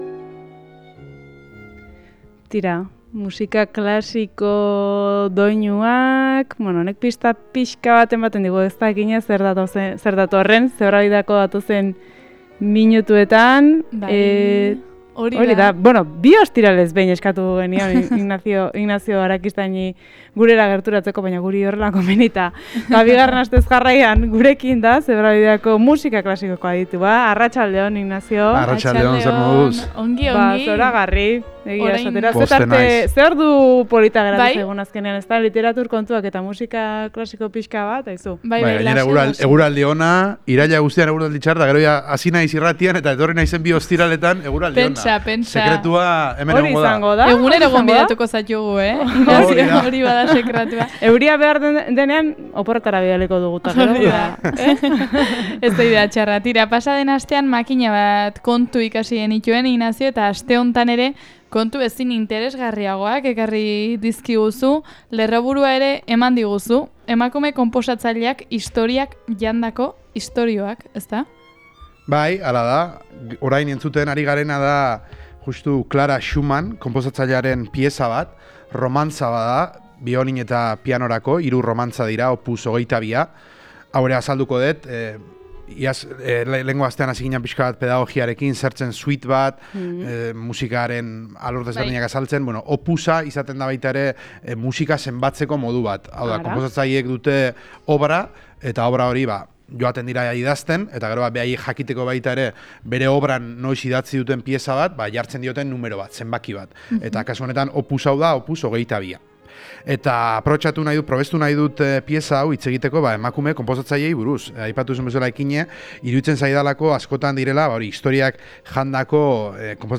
Bertze ortziral batez musika klasikoaren doinuak gailendu dira Zebrabidean.